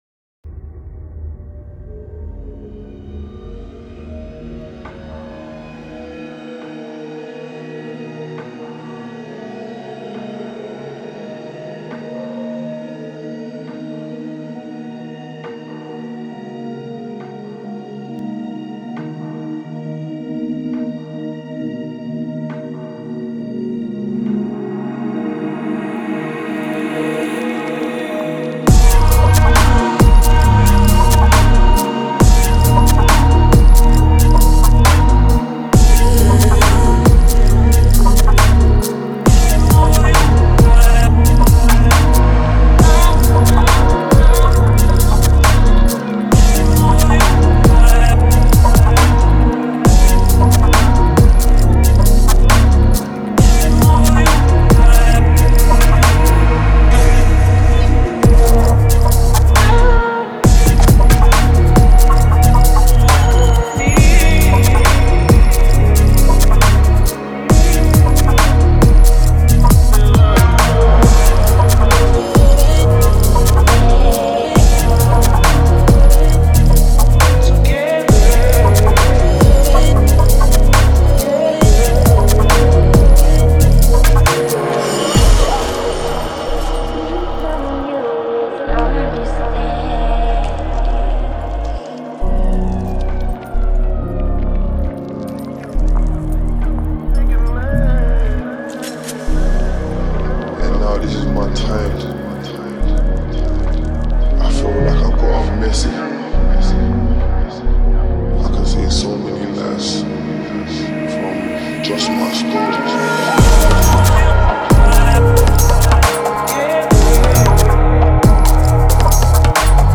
Стиль: DubStep / Chillout/Lounge / Ambient/Downtempo